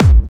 Kick 3.wav